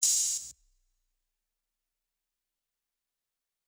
Metro Openhat [High].wav